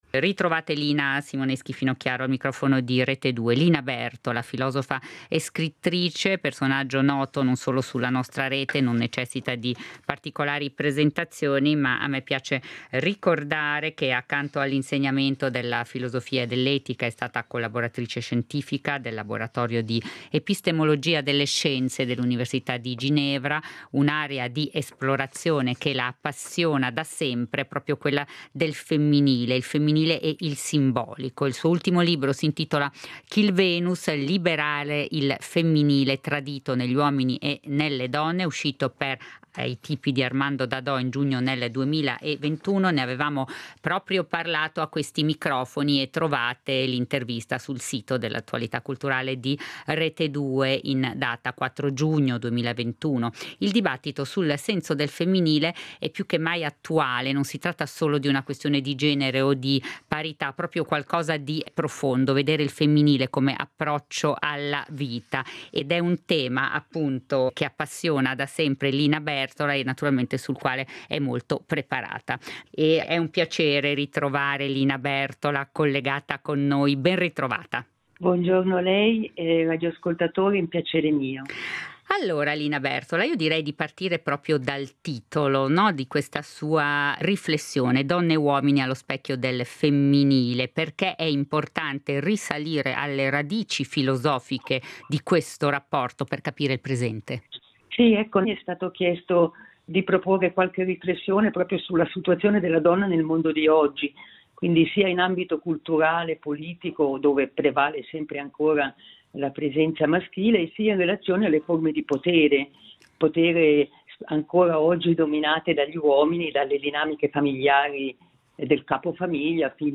Ai nostri microfoni ci propone alcune sue riflessioni sul tema.